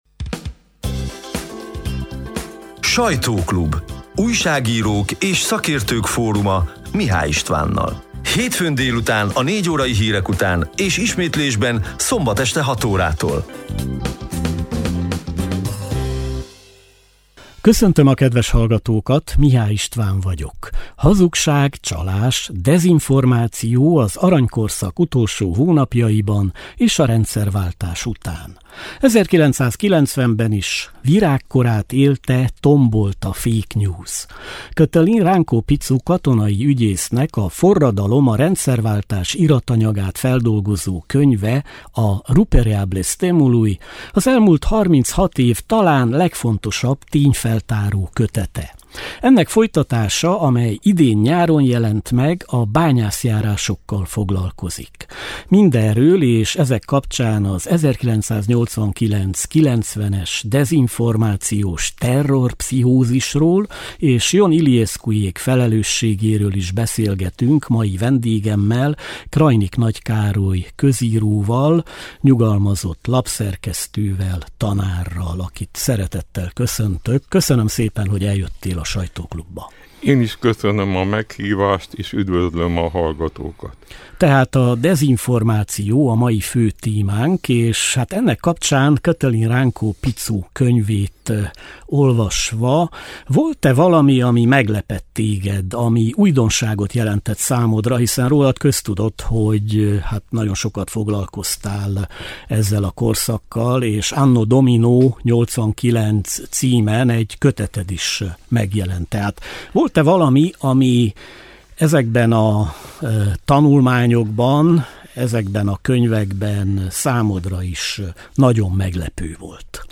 A lejásztóra kattintva a szeptember 1-jei, hétfő délutáni élő műsor szerkesztett, kissé rövidített változatát hallgathatják meg.